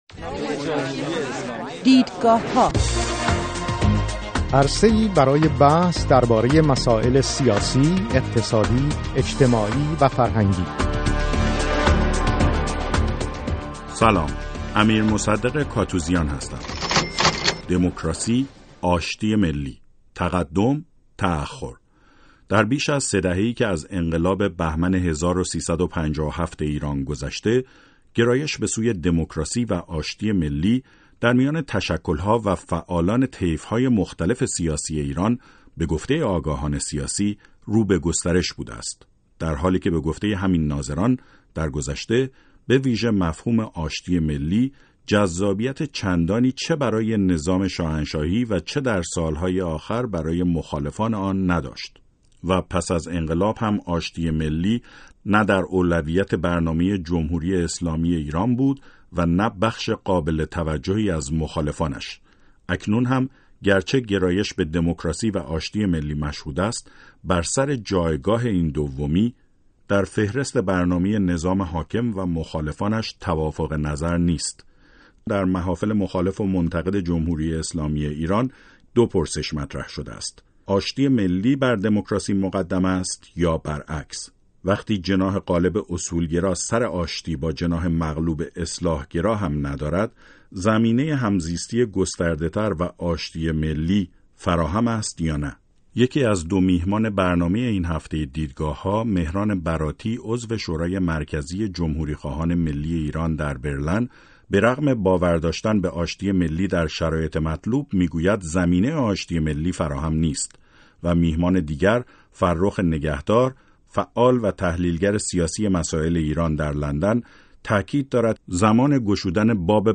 برنامه رادیویی «دیدگاه ها»